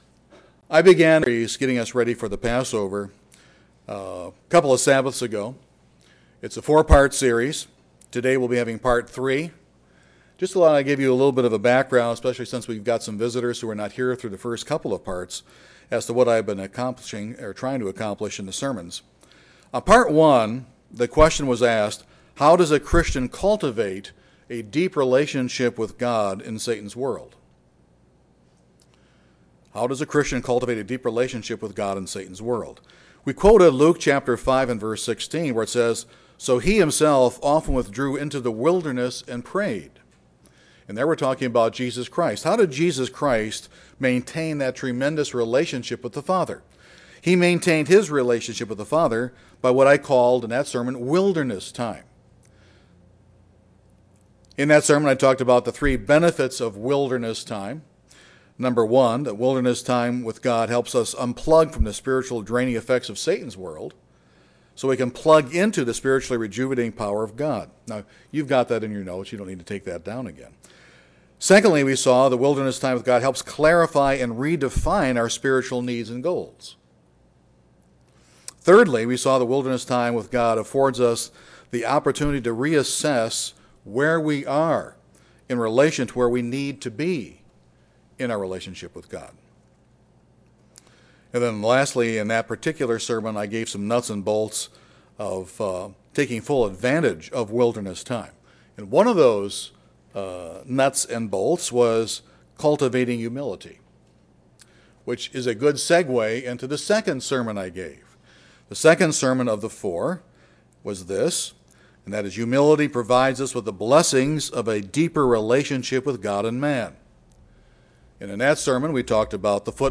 This is the second sermon in a four part series preparing us for the Passover.